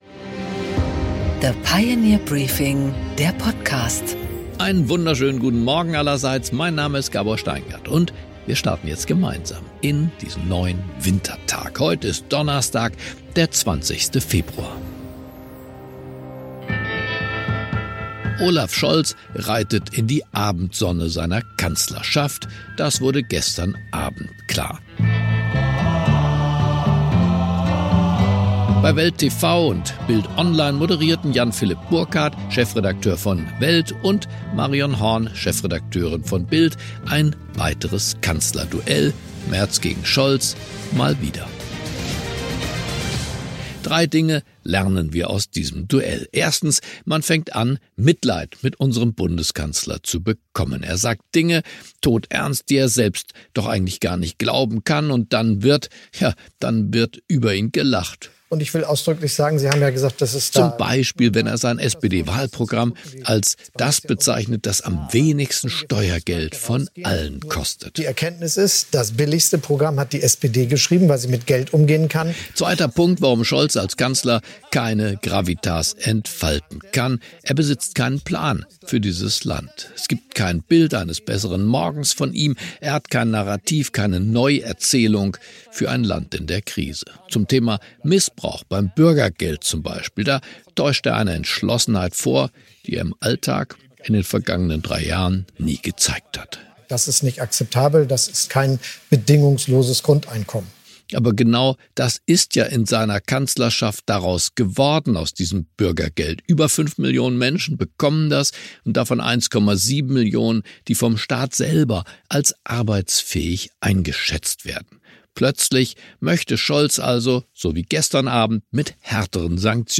Gabor Steingart präsentiert das Pioneer Briefing
Interview: Sebastian Kurz, ehemaliger österreichischer Bundeskanzler und heutiger Unternehmer, spricht mit Gabor Steingart über die bevorstehenden Wahlen in Deutschland, die schwierige Weltlage und seine Sicht auf die Friedensverhandlungen in Riad.